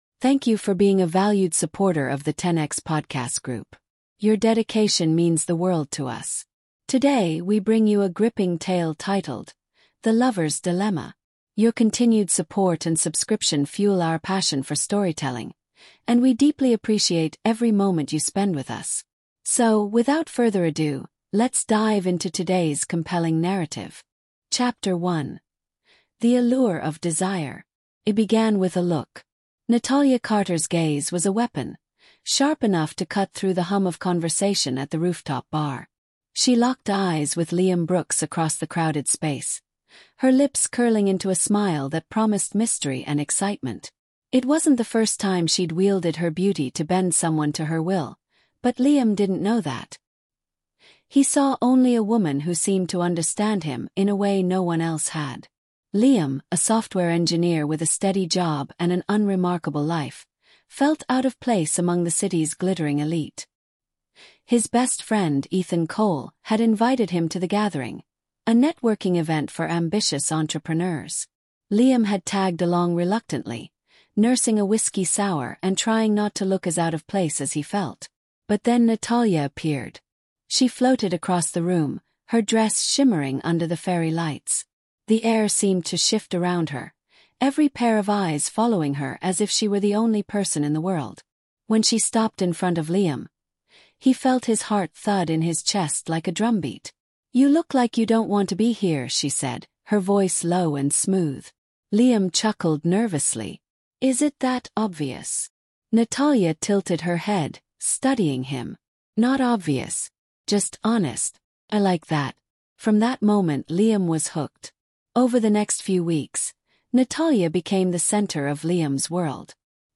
The Lover’s Dilemma is a gripping storytelling podcast that delves deep into the dark art of manipulation, betrayal, and redemption. Follow the journey of Liam Brooks, a man ensnared by the charm of Natalia Carter, a master manipulator with a secret agenda. As lies unravel and loyalties are tested, Liam must confront his darkest truths to undo the damage he helped create.